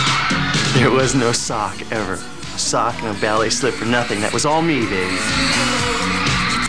Sound bytes were captured from the vh-1 Special of Leif Garrett:  Behind The  Music and Where Are They Now.